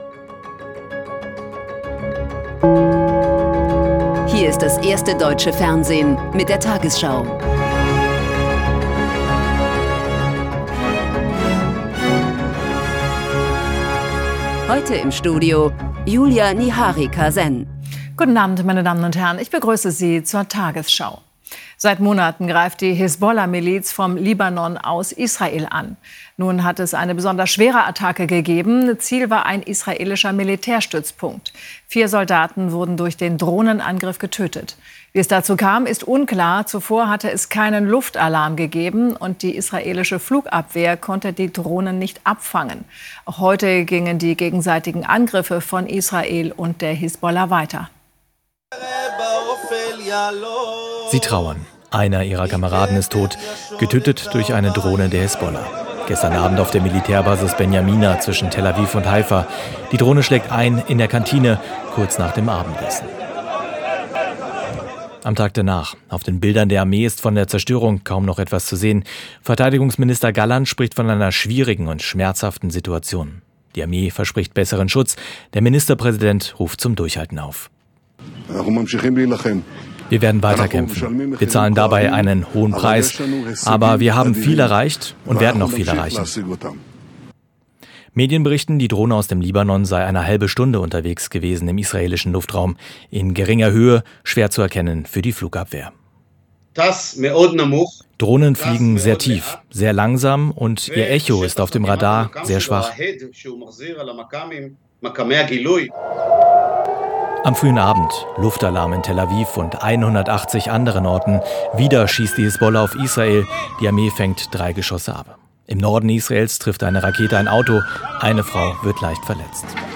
Deutschlands erfolgreichste Nachrichtensendung als Audio-Podcast.